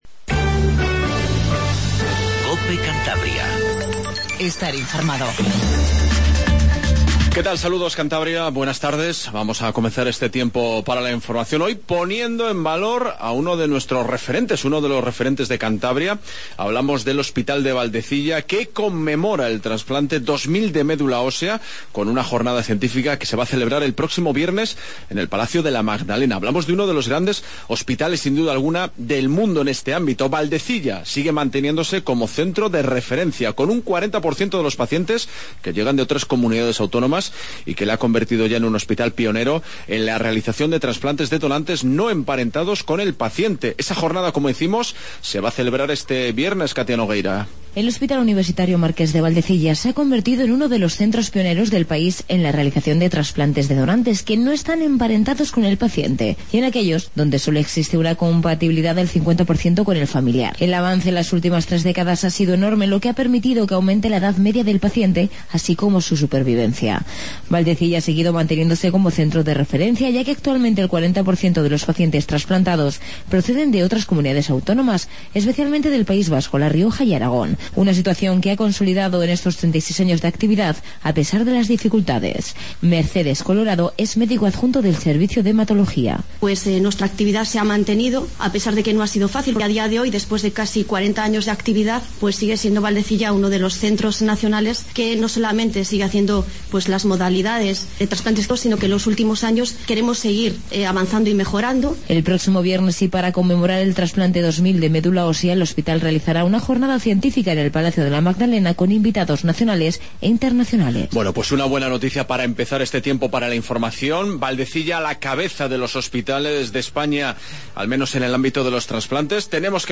INFORMATIVO REGIONAL 14:20